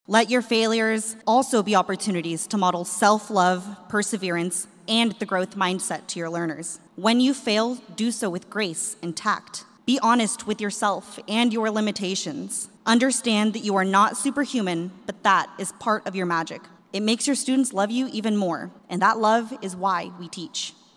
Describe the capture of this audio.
Fall commencement ceremonies were held at Kansas State University over the weekend.